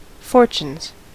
Ääntäminen
Ääntäminen US UK : IPA : /fɔː(ɹ).tjuːn/ Haettu sana löytyi näillä lähdekielillä: englanti Käännöksiä ei löytynyt valitulle kohdekielelle.